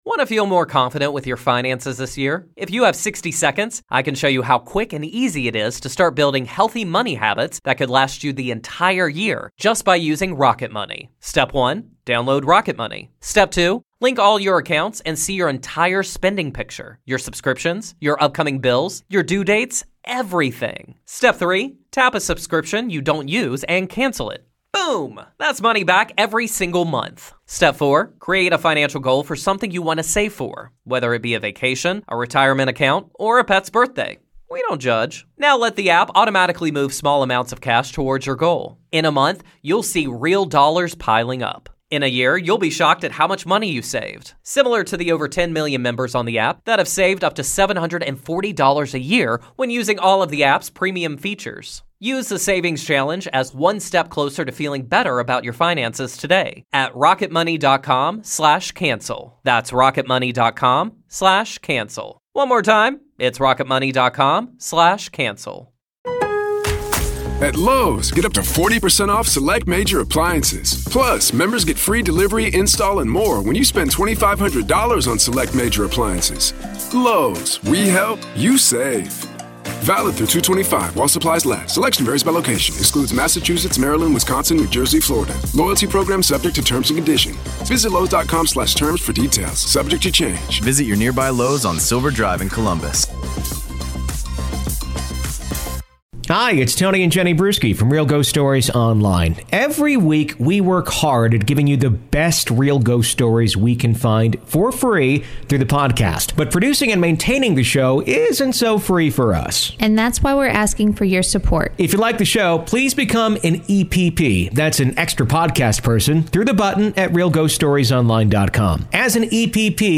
A woman tells of her husband's encounter with a mysterious figure and the gifts he received from it.